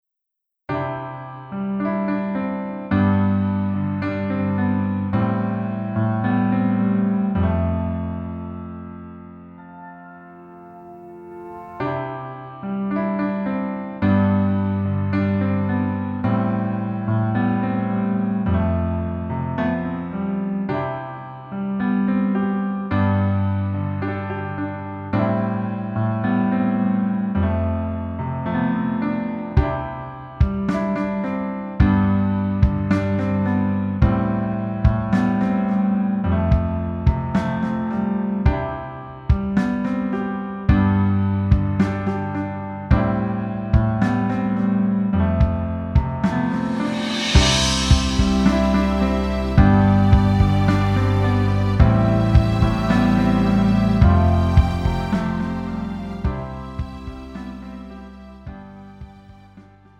음정 원키
장르 가요 구분 Pro MR